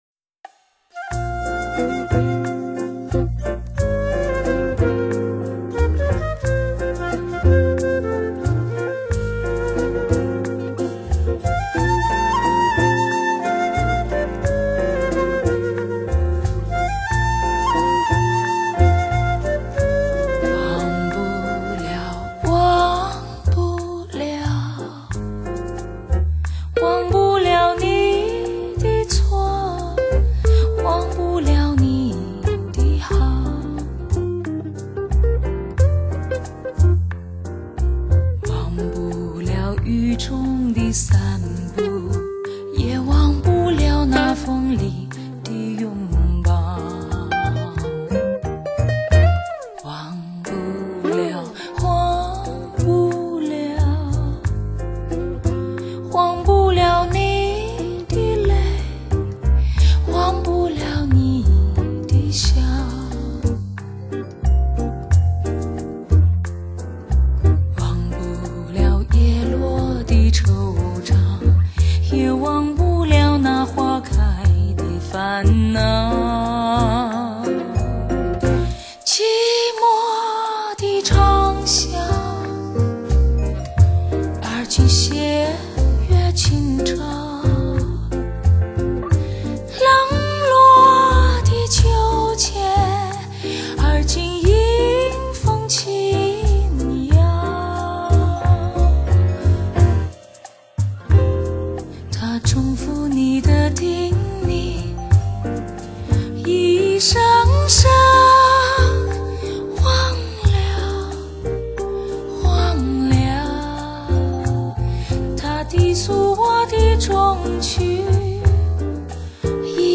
拉丁风格注入经典，结合歌者游刃有余的驾驭，新意油然
倾斜的声场